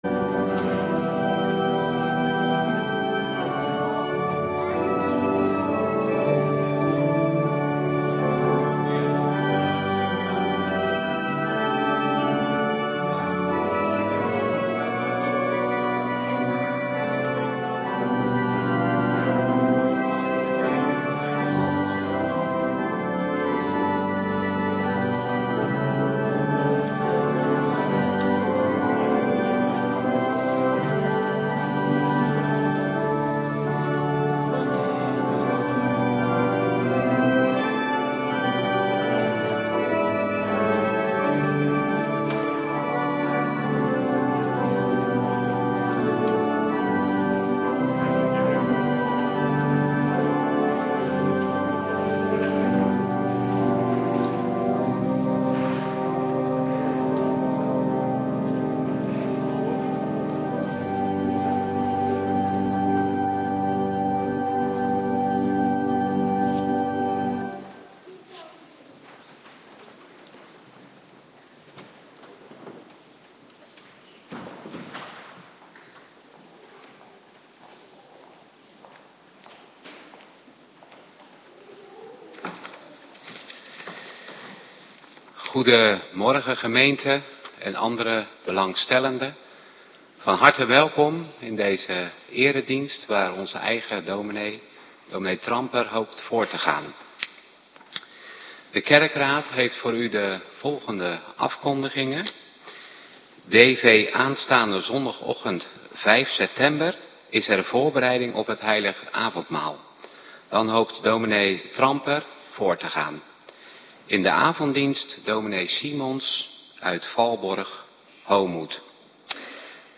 Morgendienst - Cluster A
Locatie: Hervormde Gemeente Waarder